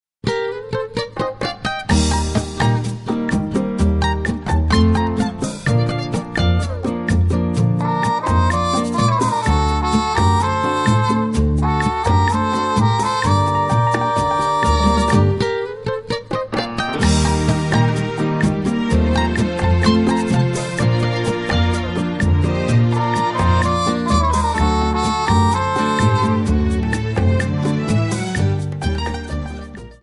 Backing track files: All (9793)